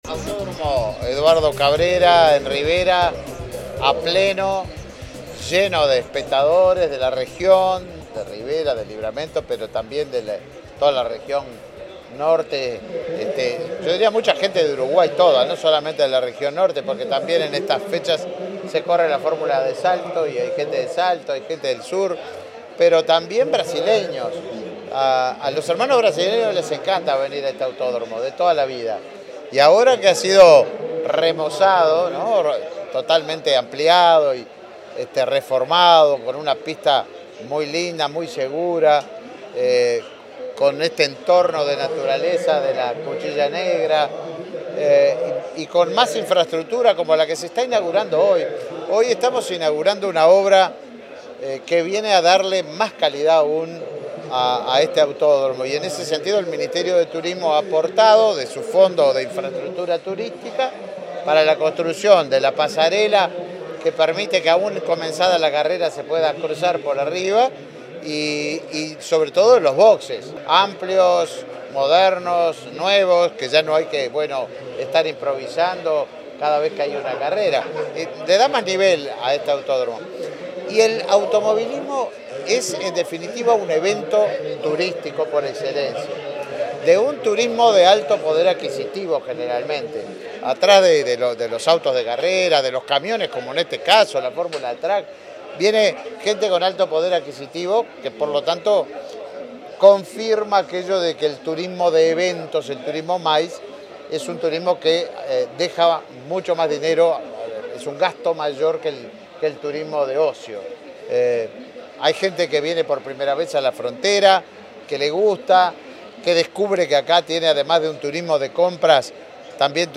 Declaraciones del ministro de Turismo, Tabaré Viera